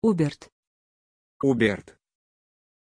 Aussprache von Hubert
pronunciation-hubert-ru.mp3